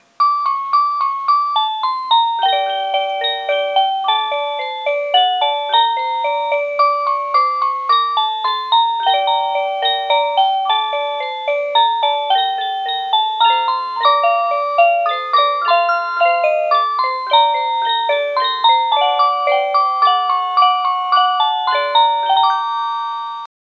06-Cuckoo-Tune.mp3